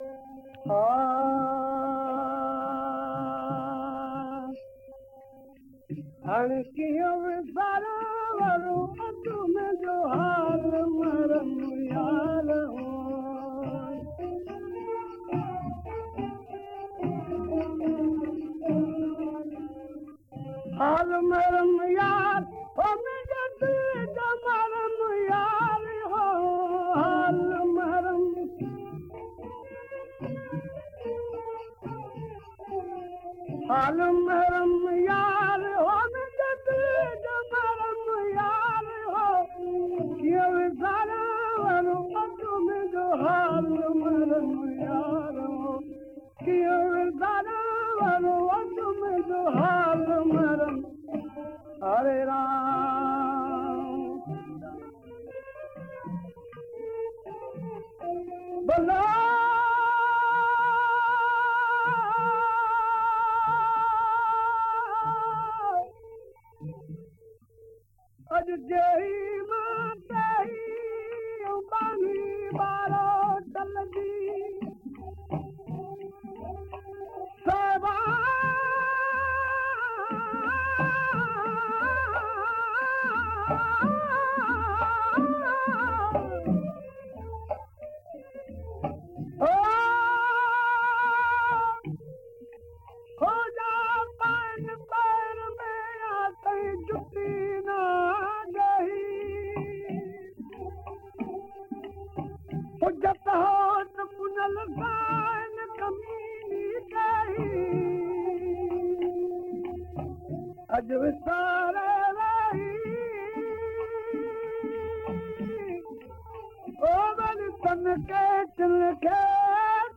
recorded in the british era
soul satisfying original voice